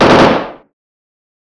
made a better mechine gun Smile
Erm, I have to say, the only really usable one is gun burst 3, but all of them, it included, are too echoy and distant, if you get what I'm saying.
gun_burst_3_213.wav